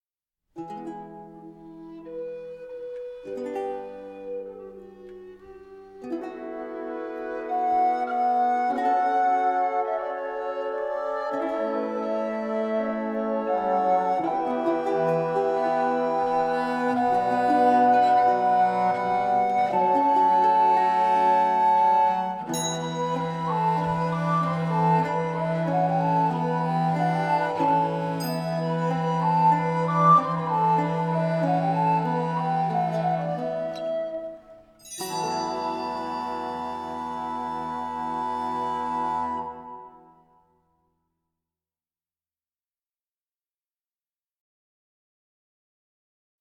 Musique de scène
Interlude
la musique originale pour chœur et orchestre de chambre